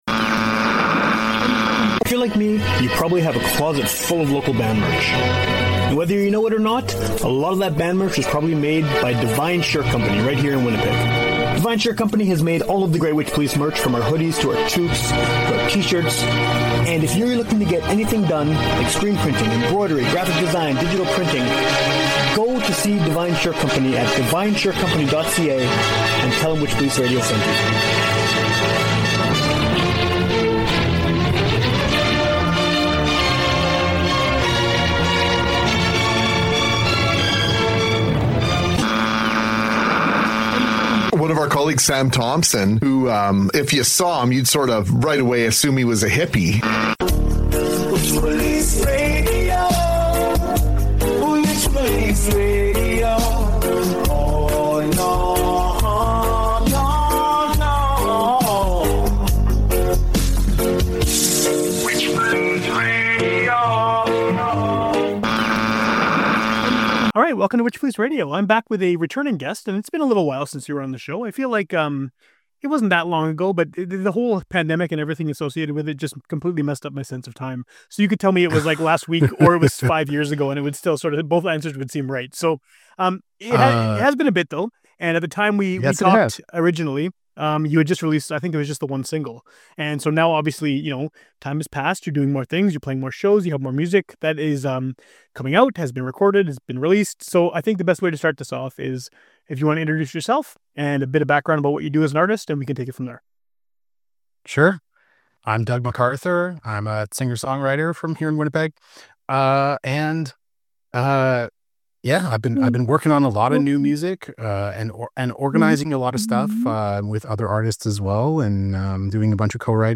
No idea why that's there, but it doesn't take away from the conversation and is very brief